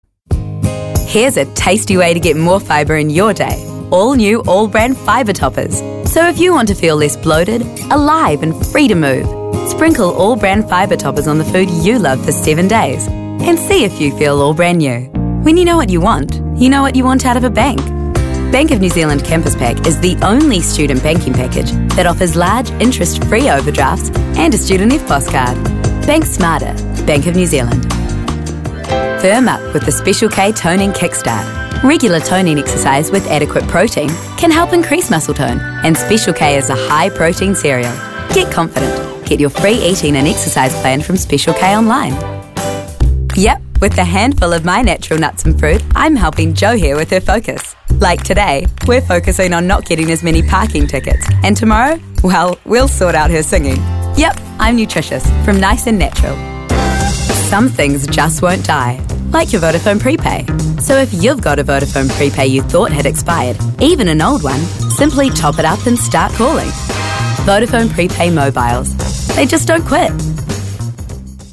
Demo
Adult, Young Adult
English | New Zealand
standard us
commercial
husky
warm